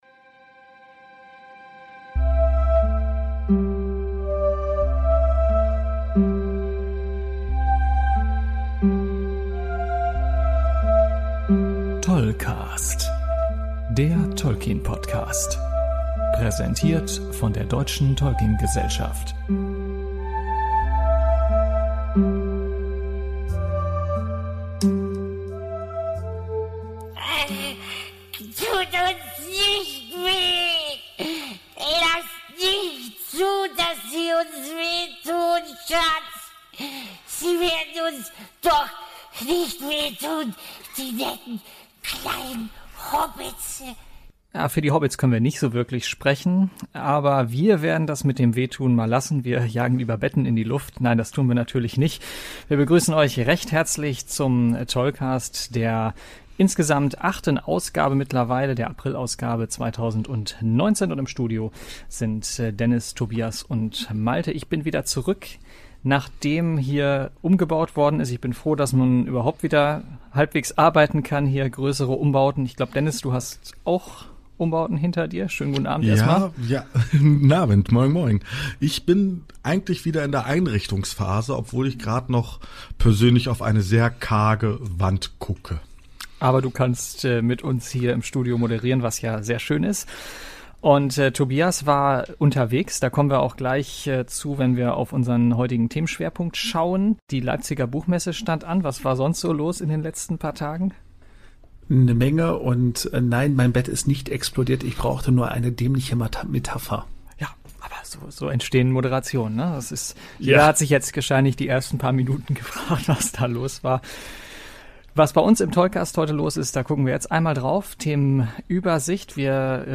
Es gibt dieses Mal einige O-Töne und auch mal tiefergehende Informationen zu Tolkien.